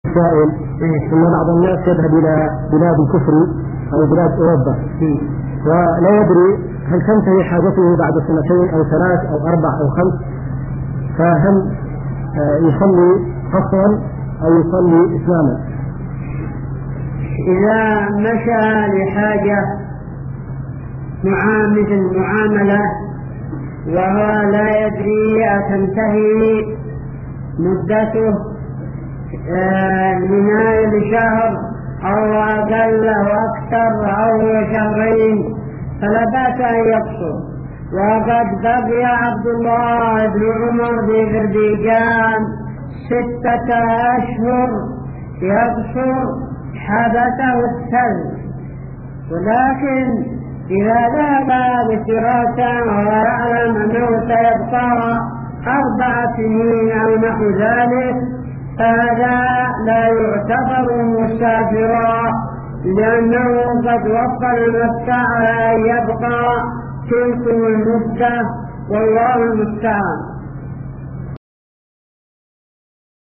-------------- من شريط : ( محاضرة وأسئلة هاتفية من إيرلندا )